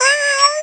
meow3.wav